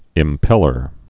(ĭm-pĕlər)